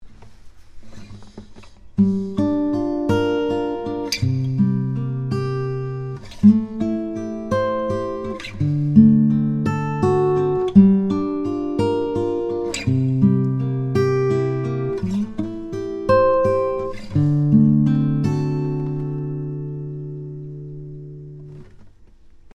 The main point is to hear what these 7th chords sound like together in a sequence.
7th chord progression example 5
Progression 4 chords are G minor 7, C 7, A minor 7, and D 7.